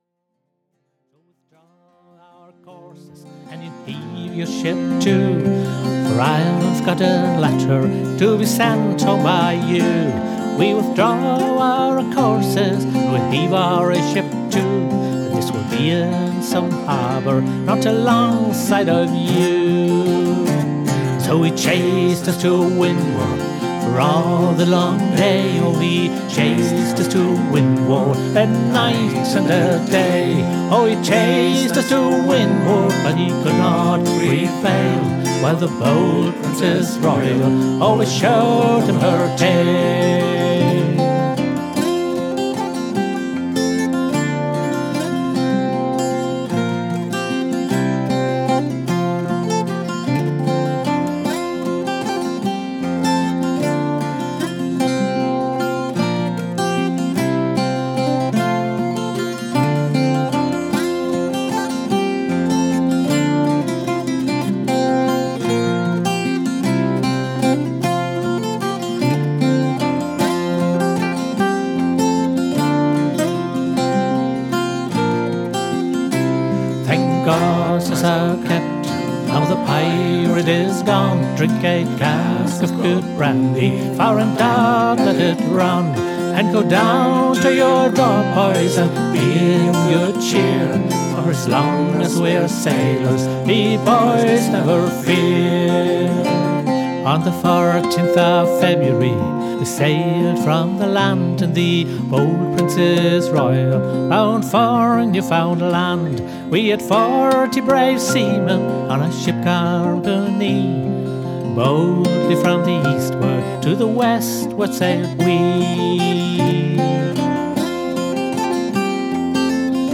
spielt Bouzouki, Mandoline, Mandola, Banjo, Geige, Ukulele, Gitarre und Tin Whistle.
Bold Princess Royal (engl. Traditional)